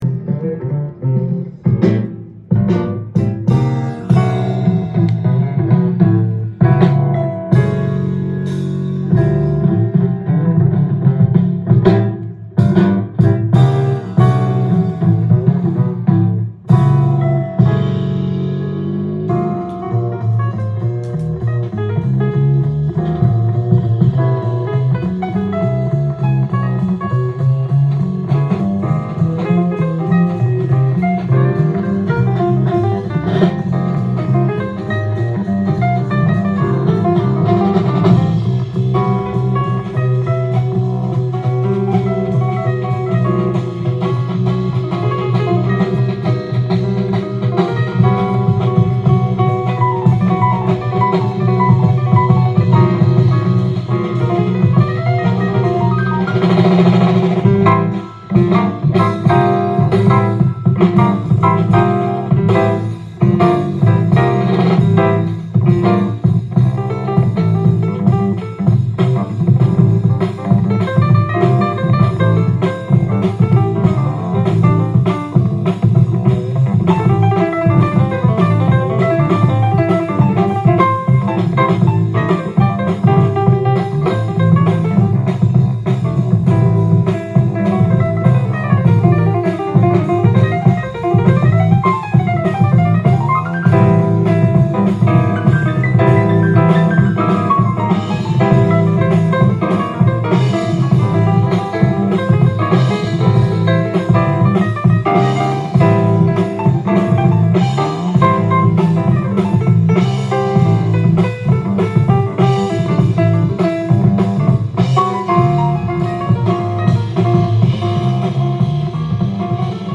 ジャンル：FUSION
店頭で録音した音源の為、多少の外部音や音質の悪さはございますが、サンプルとしてご視聴ください。
音で語り合い、ディベートするグルーブ。